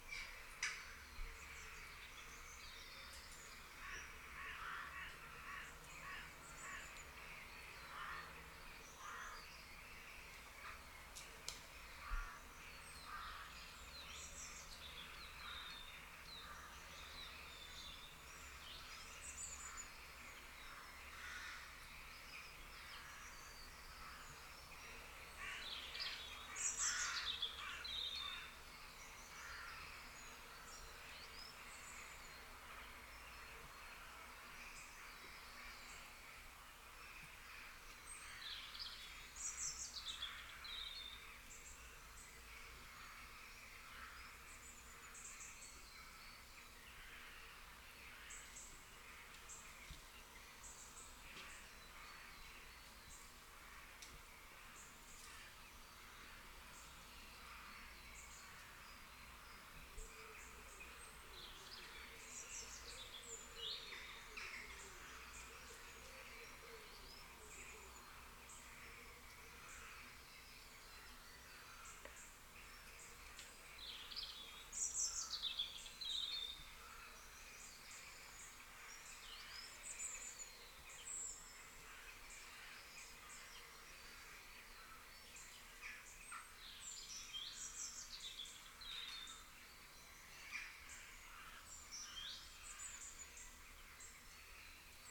Selkirkshire Nature Ambience
ambience ambient atmosphere birds general-noise nature soundscape sound effect free sound royalty free Memes